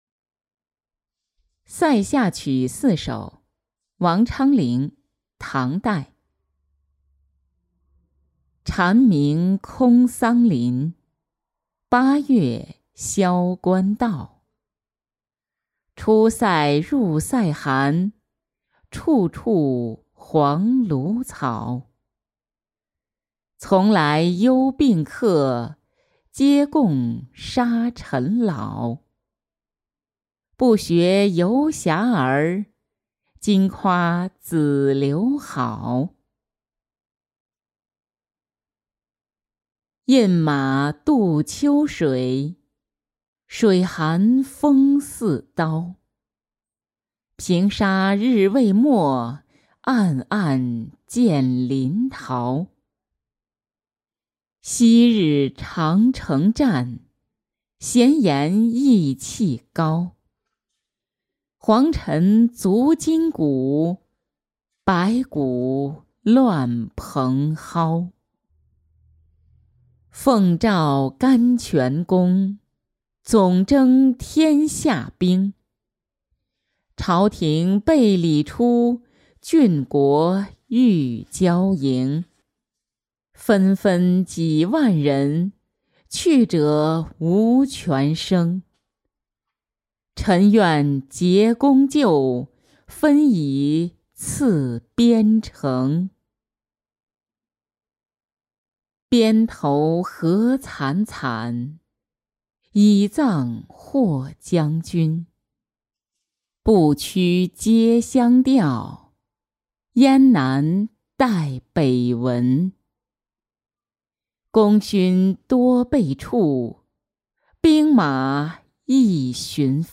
塞下曲四首-音频朗读